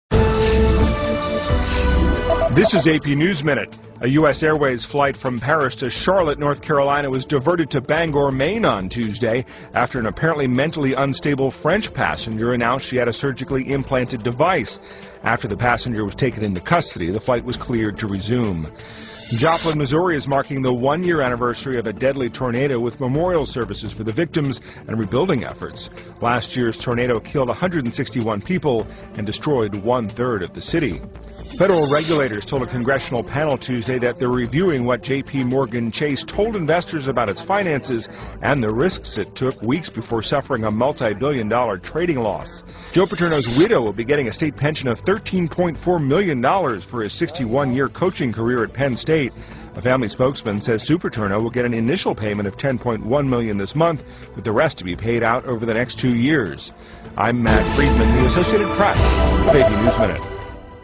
在线英语听力室AP 2012-05-26的听力文件下载,美联社新闻一分钟2012,英语听力,英语新闻,英语MP3-在线英语听力室